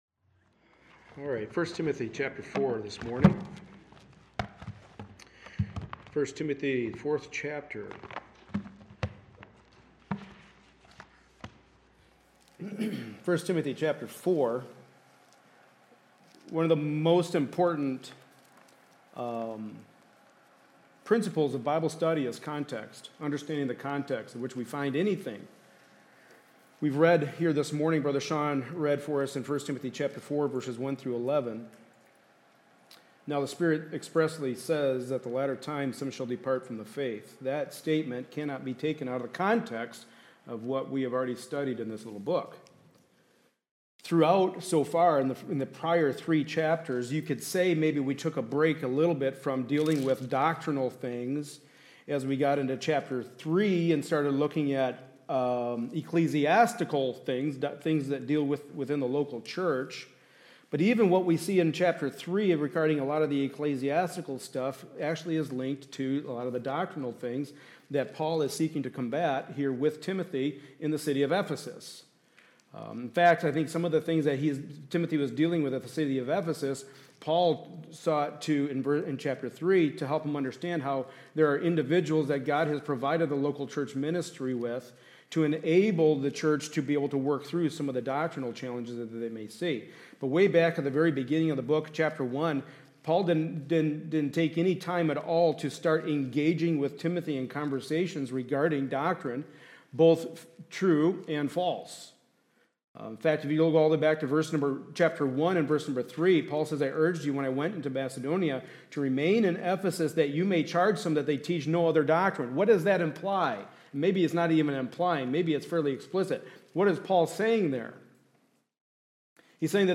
1 Timothy 4:1-11 Service Type: Sunday Morning Service A study in the Pastoral Epistles.